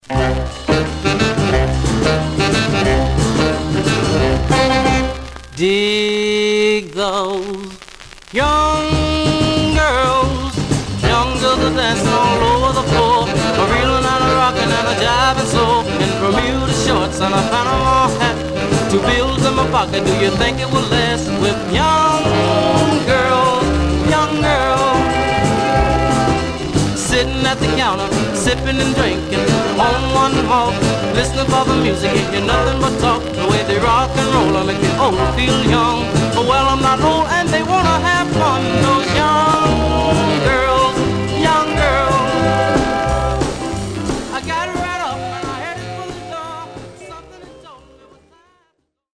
Much surface noise/wear
Mono
Rythm and Blues